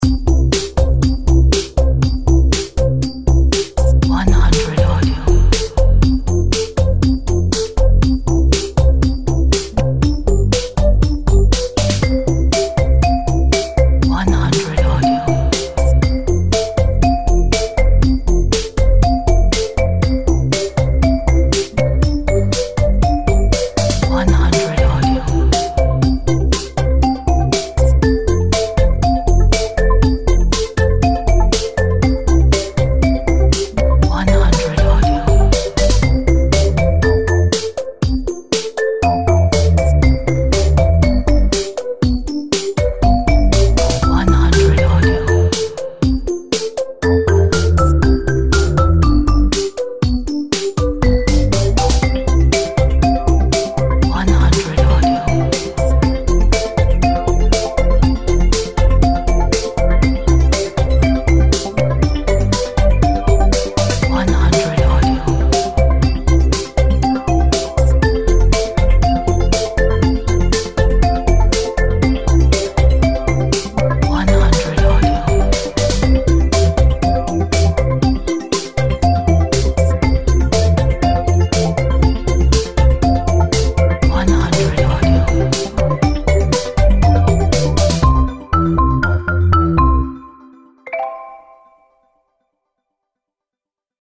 A jumpy and happy electronic tune full of positive energies.
Featuring a marimba and various synths.